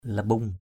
/la-ɓuŋ/ (cv.) rambung rO~/ 1.